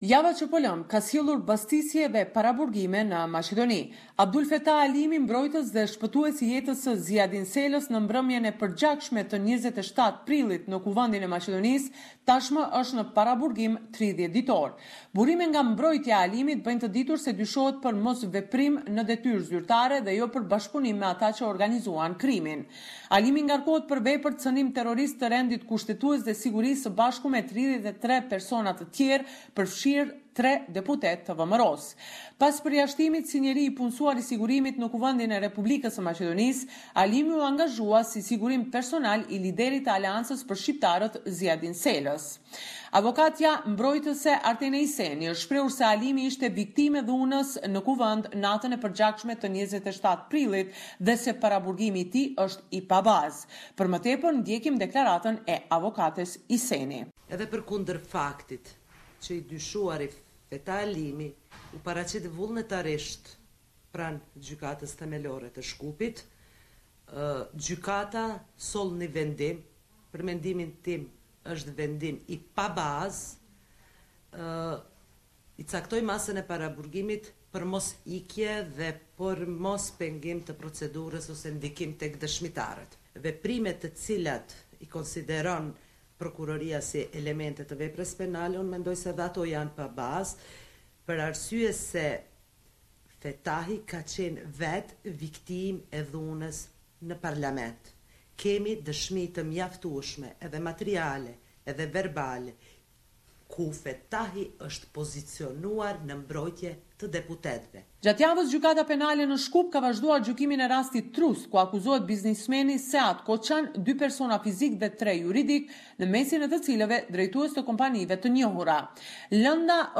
This is a report summarising the latest developments in news and current affairs in FYROM.